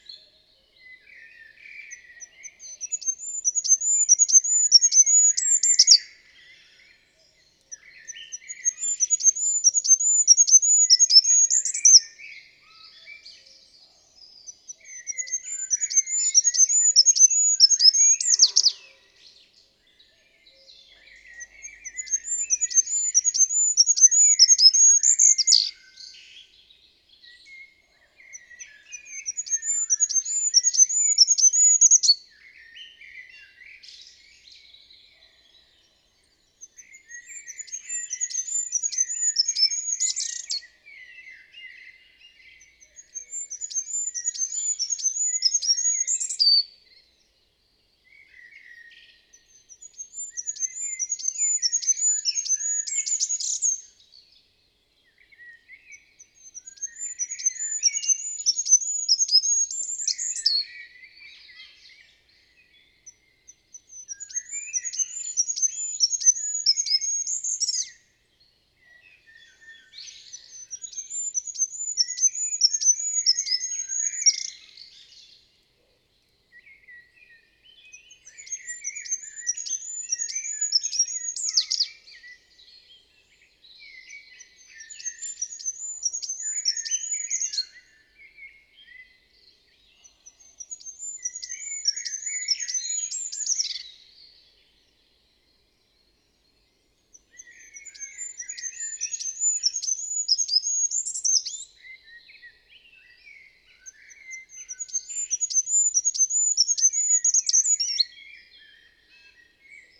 Goudhaantje geluid
• Goudhaantjes maken een uniek, hoog “zrie-zrie-zrie” geluid dat soms buiten ons gehoorbereik valt.
Het geluid van de goudhaan bestaat uit zeer hoge tonen. Deze hoge tonen komen vaak als een ritmische op- en neergaande zang. De zang eindigt meestal met een trilletje. Je hoort het “sie-sie-sie” geluid terwijl hij door de bomen beweegt.
Dit geluid is ijl en kan soms lijken op het muisachtige geluid.
Het goudhaantje zingt een ijle, hoge melodie die eindigt op een trillend geluid.